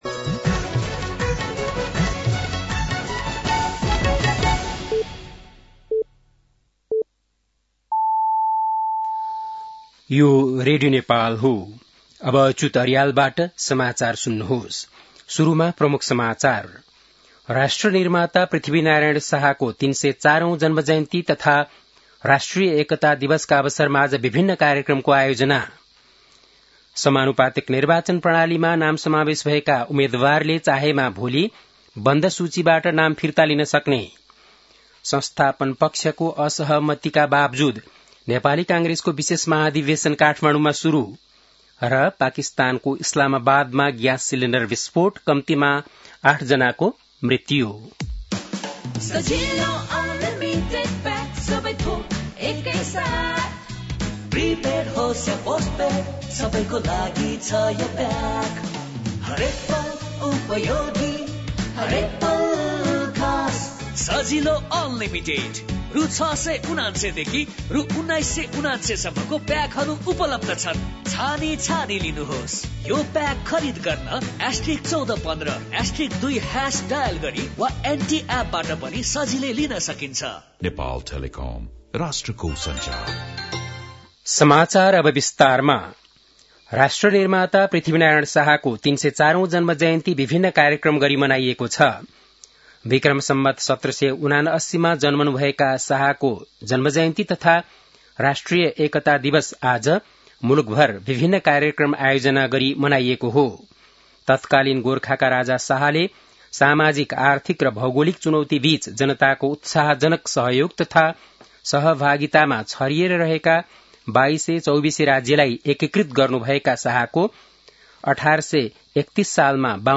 बेलुकी ७ बजेको नेपाली समाचार : २७ पुष , २०८२
7-pm-nepali-news-9-27.mp3